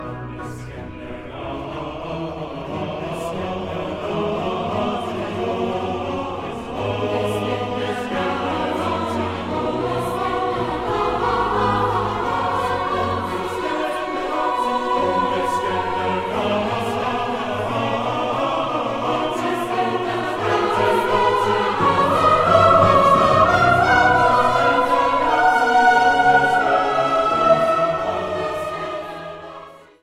Live-Aufnahme ausd dem Hohen Dom zu Fulda